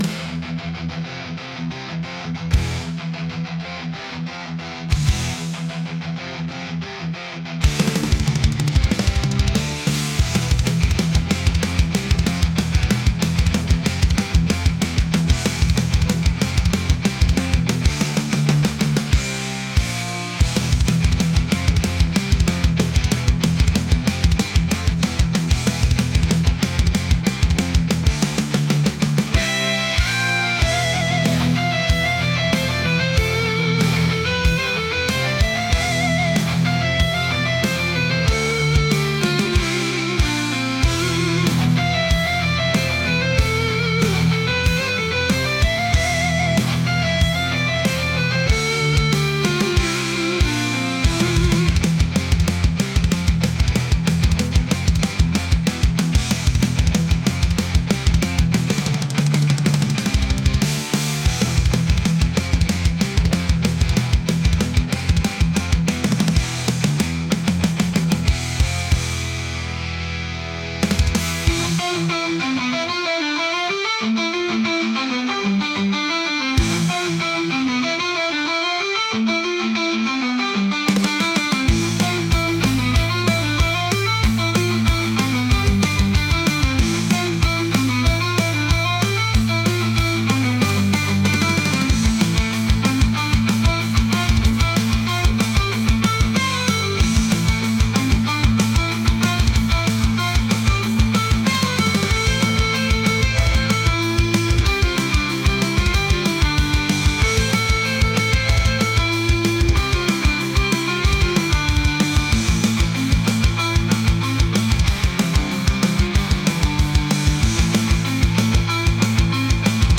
intense | heavy | metal